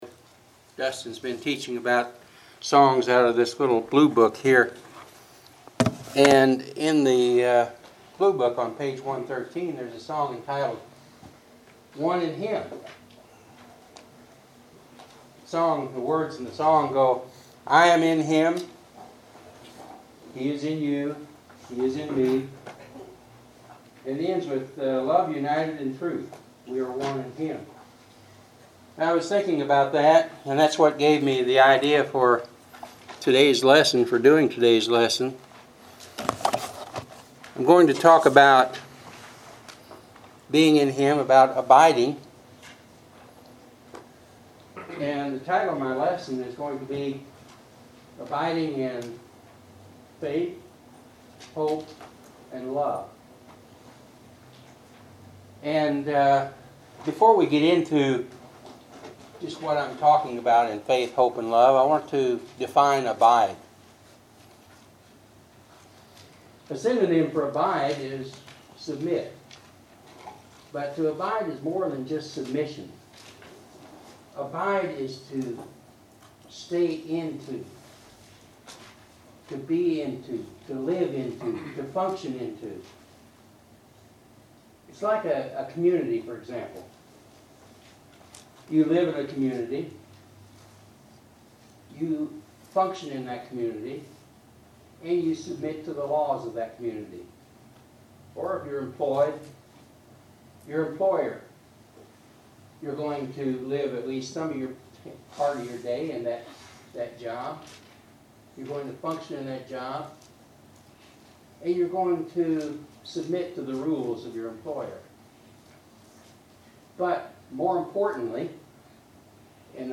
Service Type: Sunday 10:00 AM